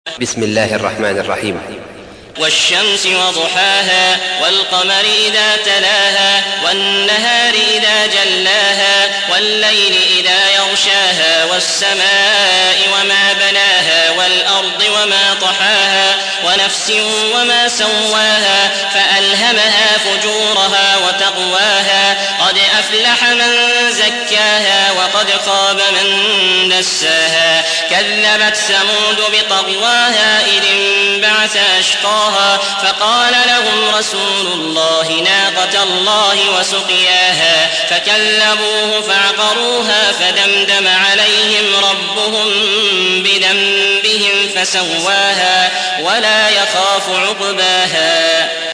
تحميل : 91. سورة الشمس / القارئ عبد العزيز الأحمد / القرآن الكريم / موقع يا حسين